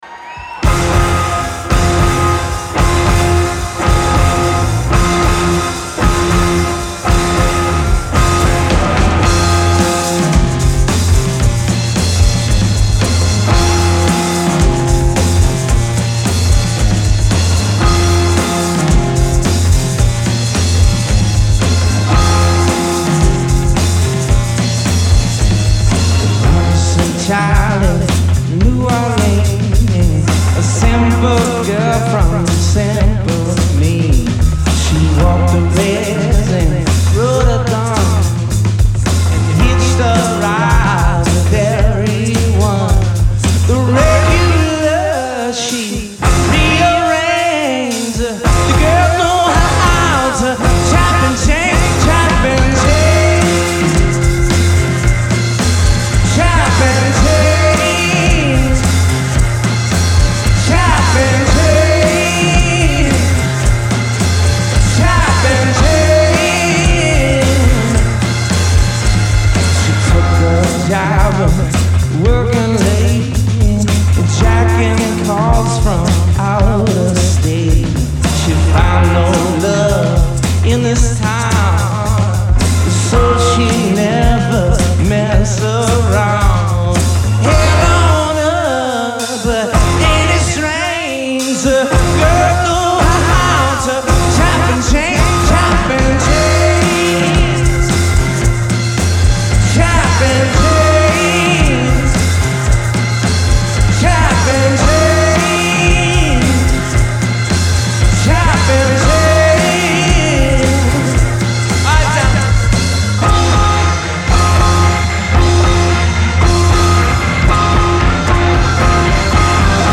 Genre : Alternative, Indie
Live in Portland, ME